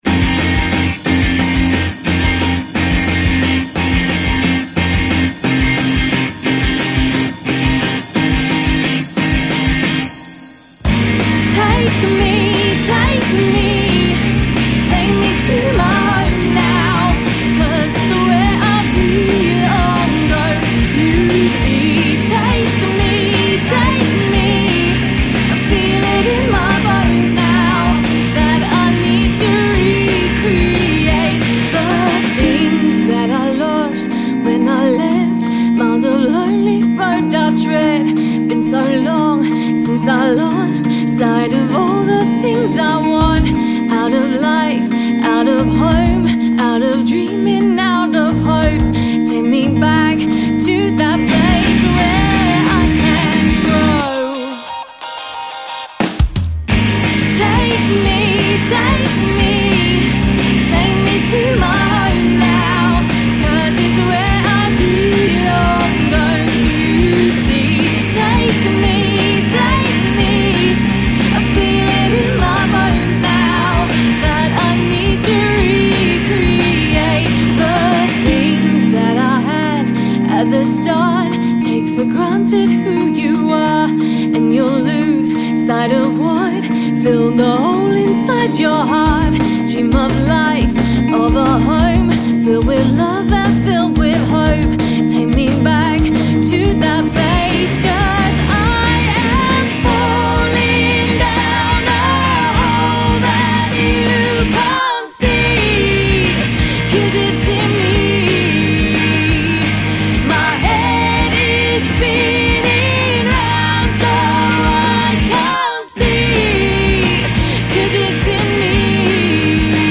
Four-piece SE England based pop-rock band